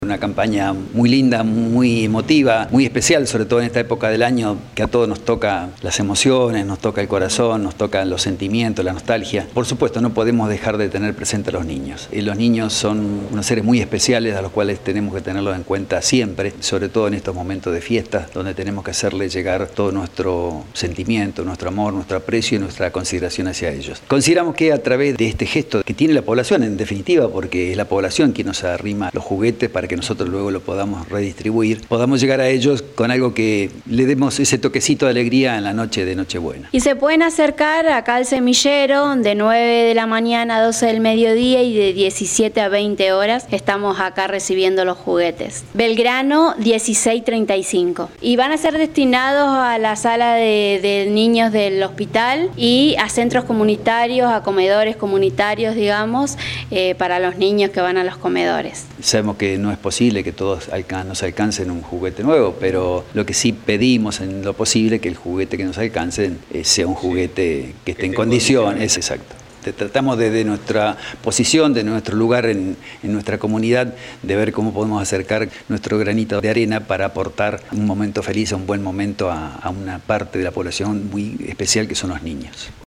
AUDIO: Integrantes de la Asociación cuentan el objetivo de la campaña.